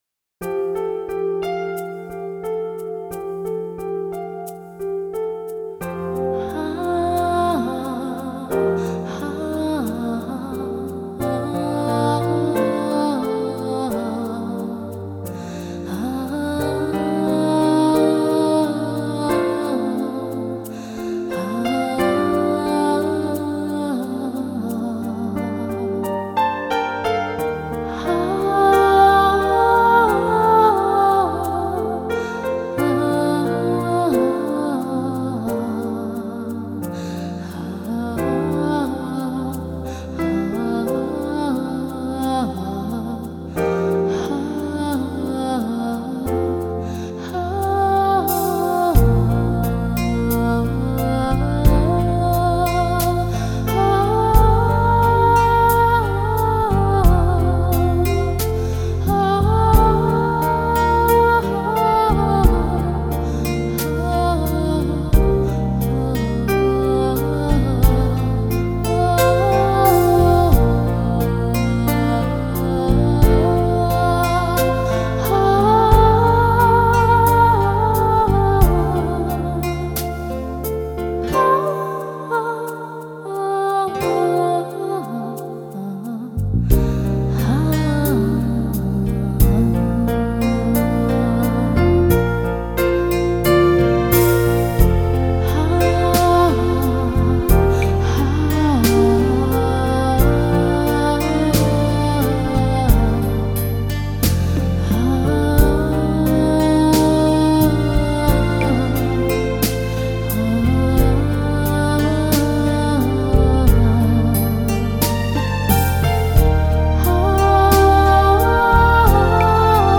演唱的同名曲子的吟唱版，曲子飘逸而动听。